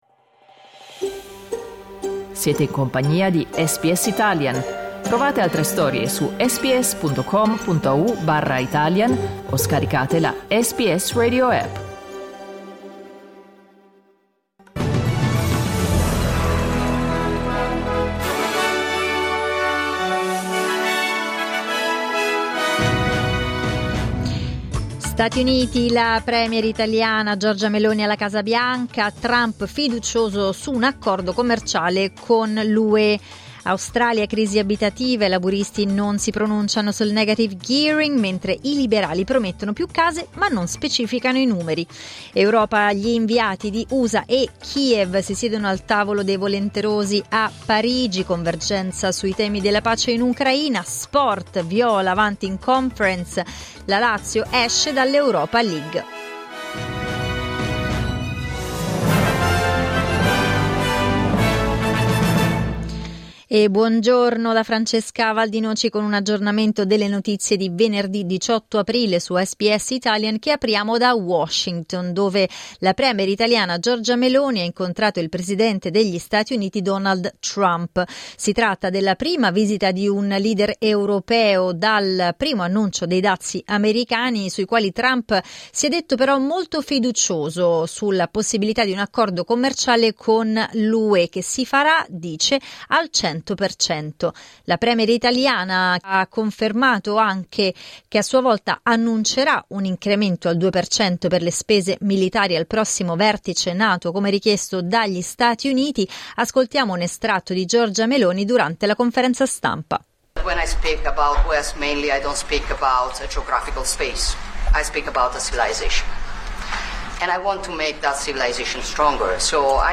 Giornale radio venerdì 18 aprile 2025
Il notiziario di SBS in italiano.